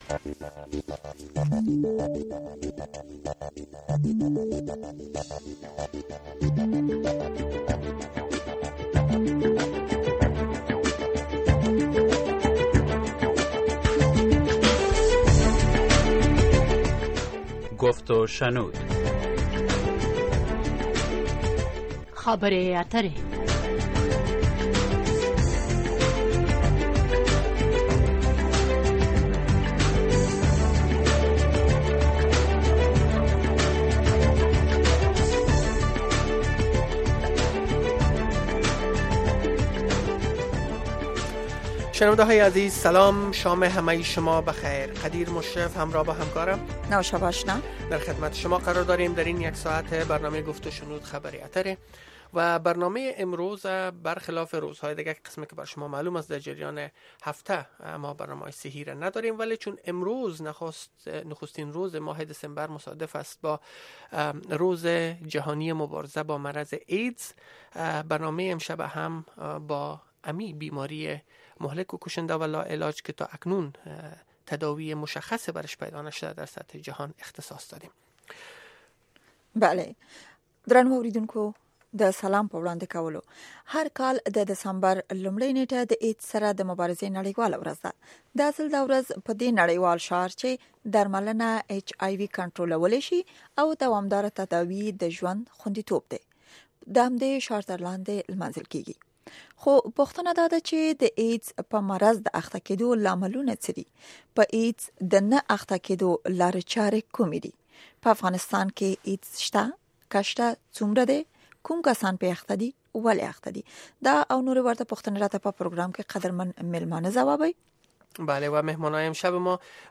The URL has been copied to your clipboard No media source currently available 0:00 1:00:50 0:00 لینک دانلود 64 kbps | ام‌پی ۳ برای شنیدن مصاحبه در صفحۀ جداگانه اینجا کلیک کنید